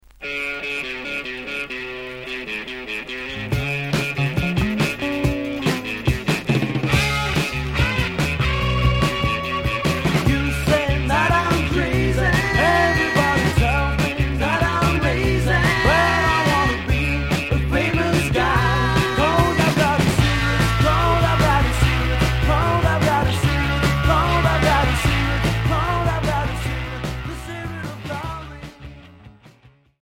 Rock Mod Unique 45t retour à l'accueil